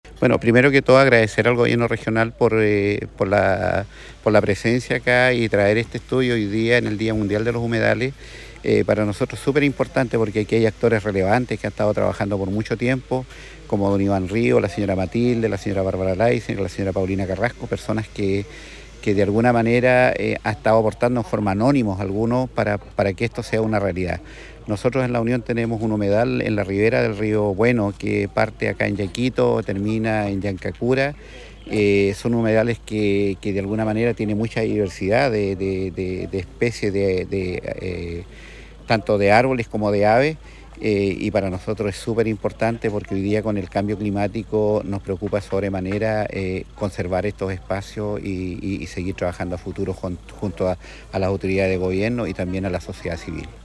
Cuña_Alcalde-La-Unión_Humedal-Trumao.mp3